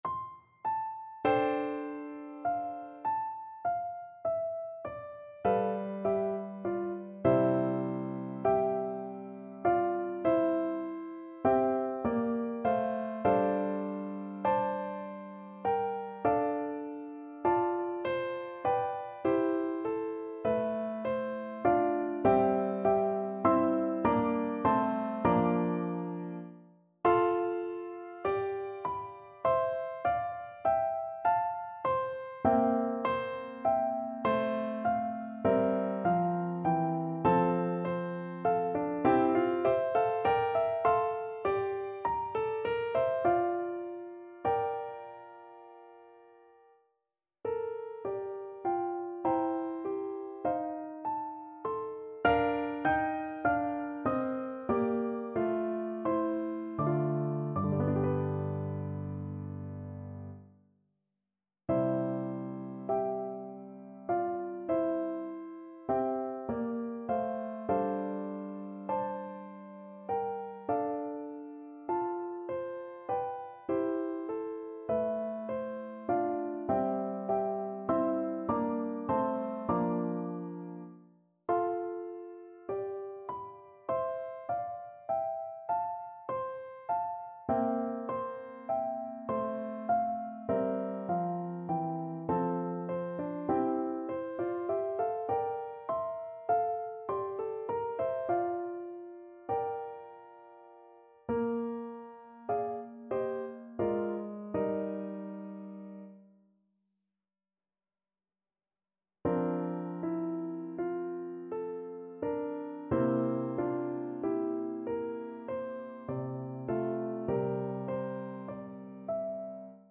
5/4 (View more 5/4 Music)
Classical (View more Classical Flute Music)